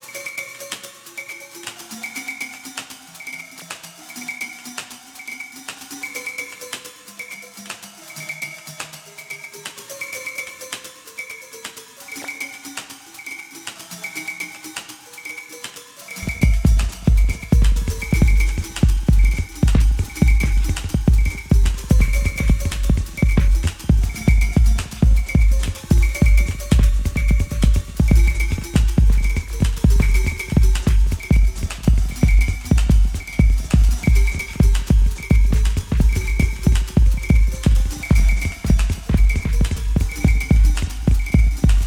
CongaTechBreakLoop.wav